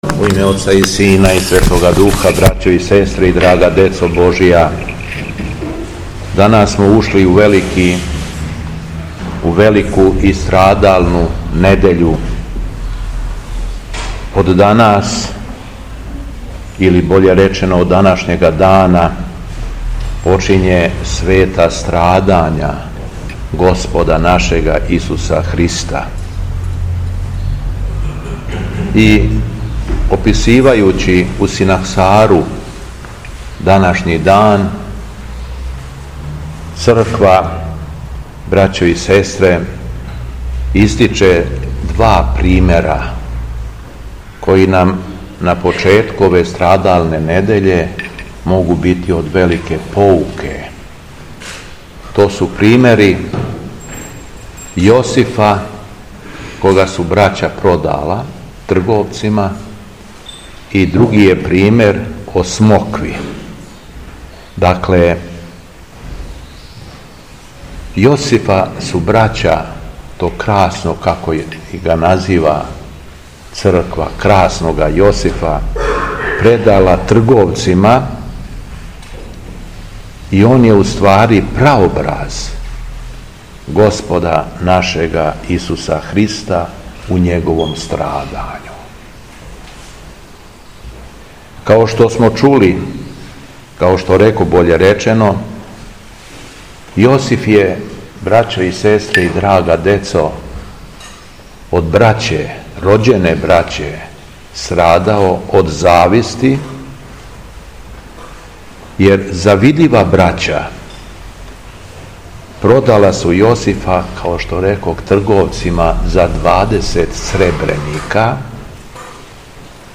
Беседа Његовог Преосвештенства Епископа шумадијског г. Јована
Након прочитаног зачала Светог Јеванђеља по Матеју епископ се обратио окупњеном верном народу: